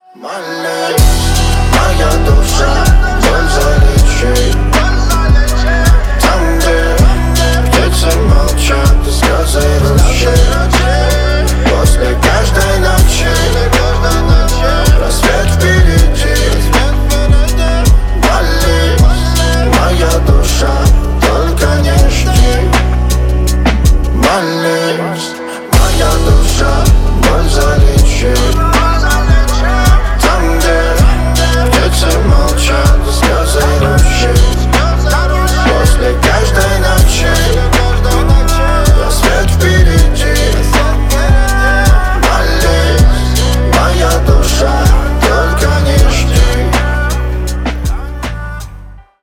• Качество: 320, Stereo
мужской голос
красивые
лирика
грустные
русский рэп
мелодичные
спокойные